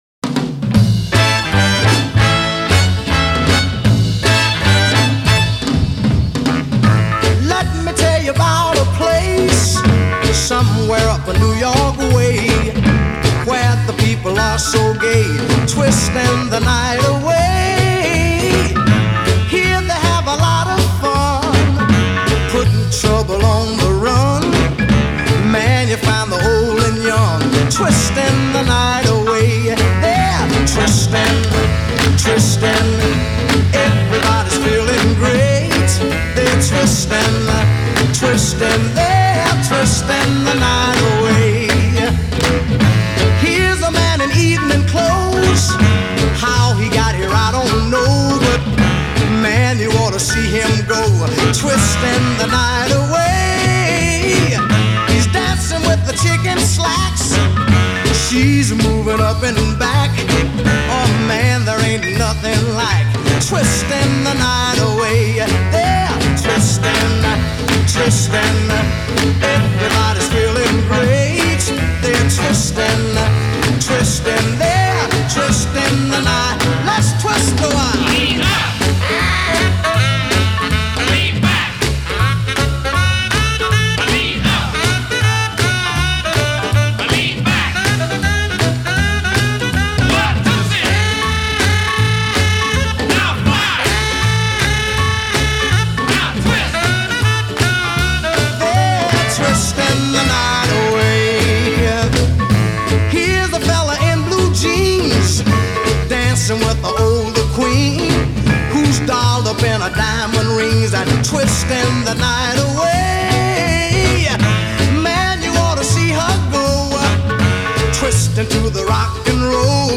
along with a great sax solo